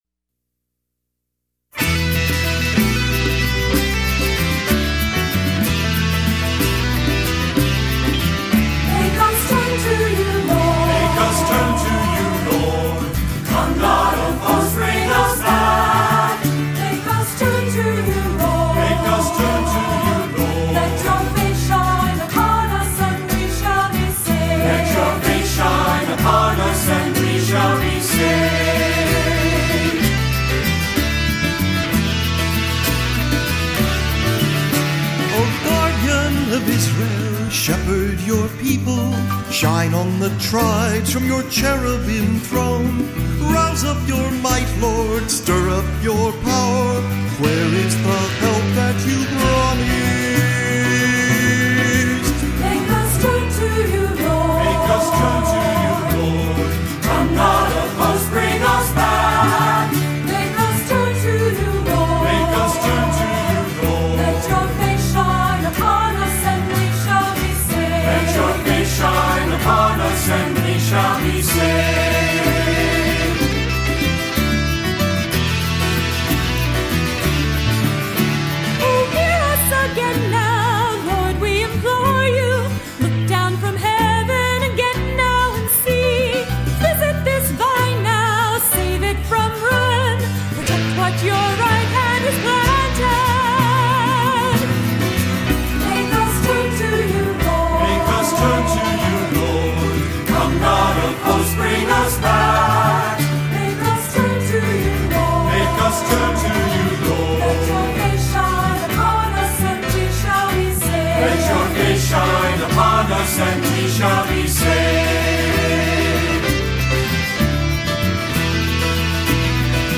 Voicing: Cantor, assembly